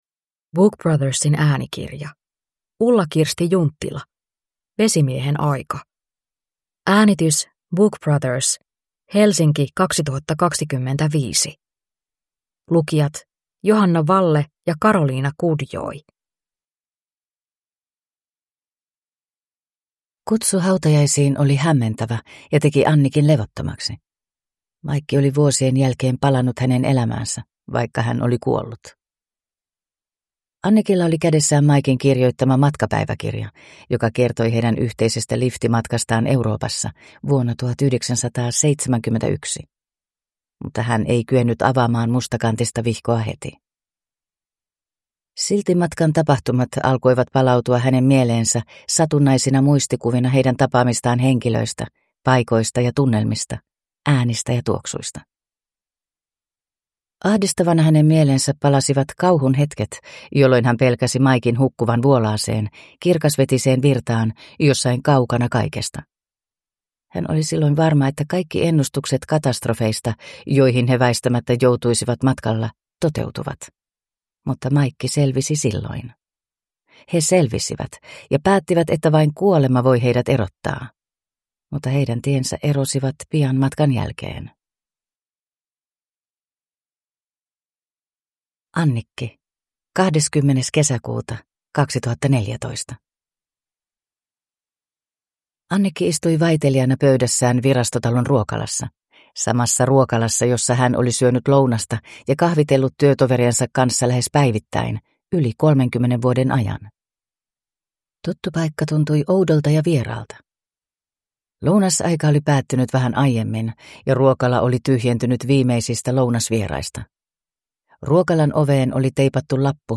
Vesimiehen aika (ljudbok) av Ulla-Kirsti Junttila